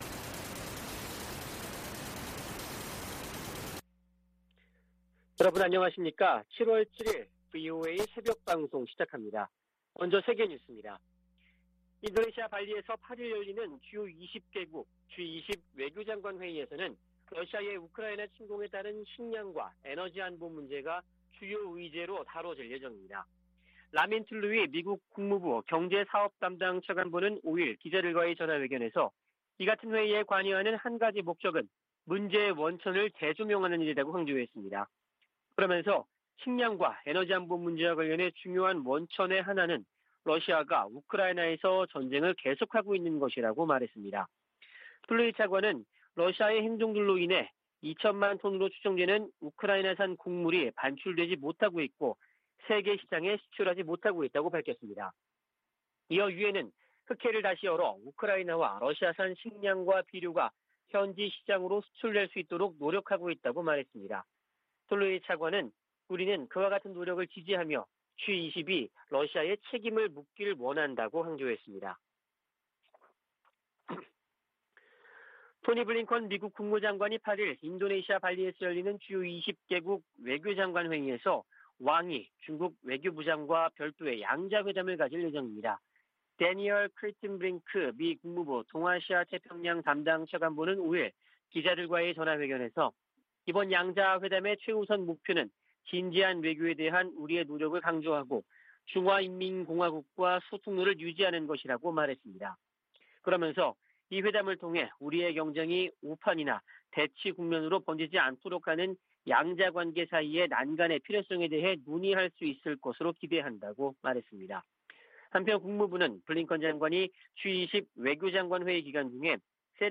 VOA 한국어 '출발 뉴스 쇼', 2022년 7월 7일 방송입니다. 바이든 정부의 동맹 강화 의지는 인도태평양 지역에서 가장 뚜렷하다고 미 국무부 동아시아태평양 담당 차관보가 말했습니다. 국제원자력기구(IAEA) 사무총장이 북한 핵 문제를 해결되지 않은 집단적 실패 사례로 규정했습니다. 미 하원에서 북한 등 적국들의 ‘회색지대 전술’에 대응을 개선토록 하는 법안이 추진되고 있습니다.